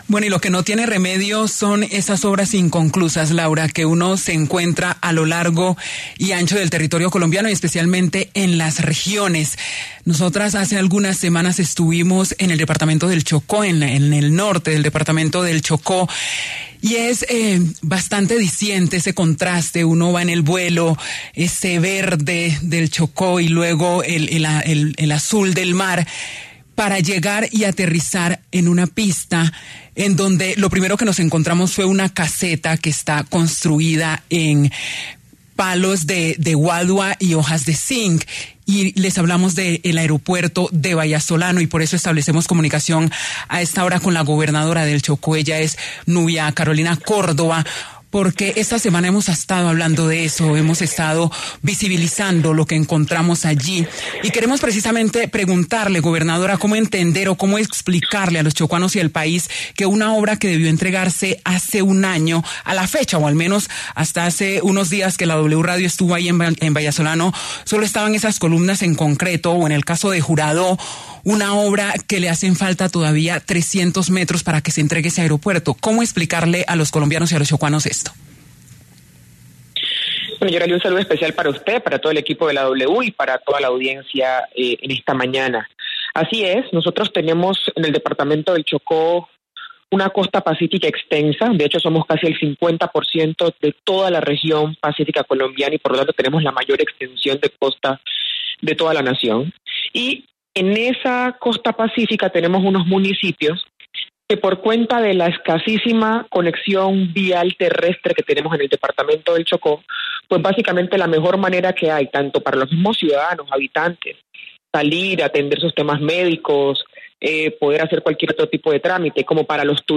Nubia Córdoba, gobernadora de Chocó, se pronunció en La W sobre las obras sin terminar en los aeropuertos de Bahía Solano y Juradó.